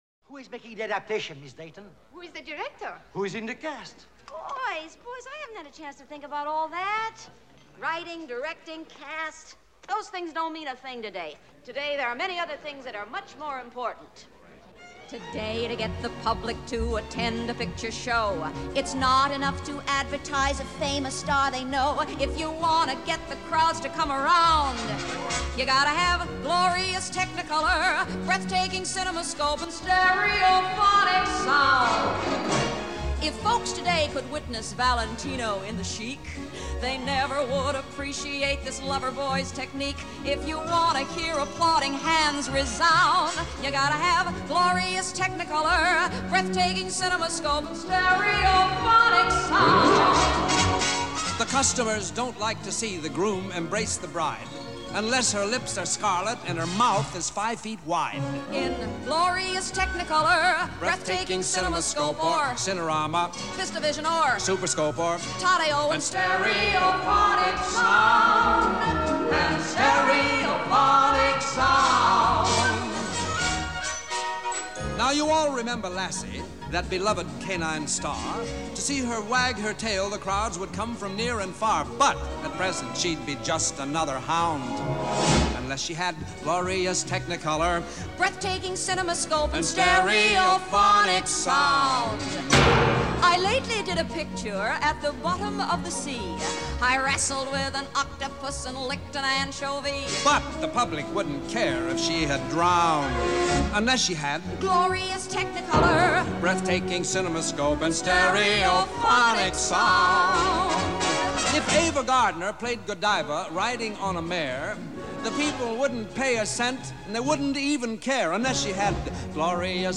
1955   Genre: Musical   Artist